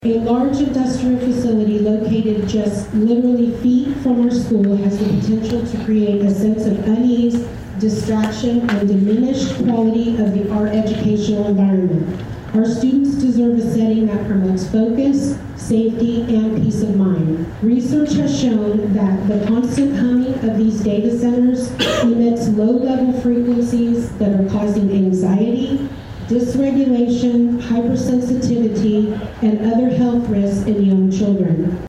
In a standing room only crowd that reached full capacity at the Dave Landrum Community Center, 14 Pawhuska residents spoke out against the possibility of a small scale data center coming to Pawhuska.